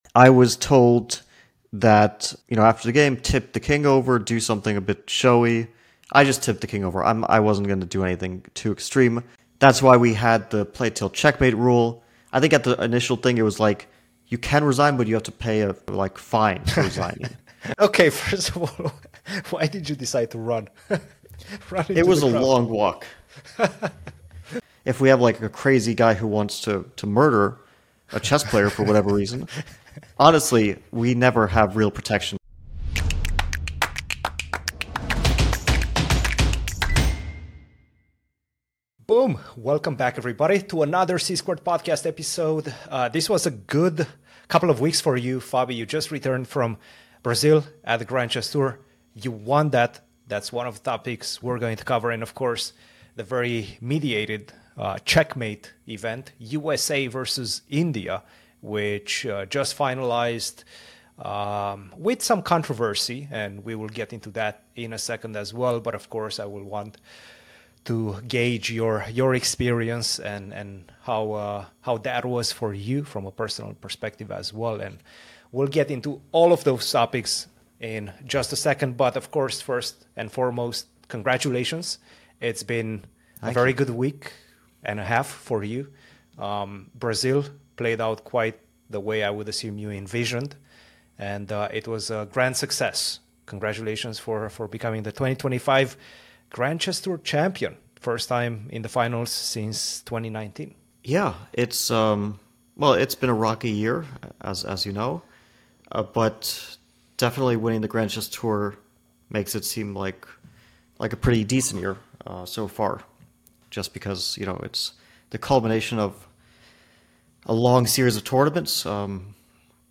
The C-Squared Podcast is an in depth weekly discussion about the chess world with your hosts